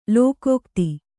♪ lōkōkti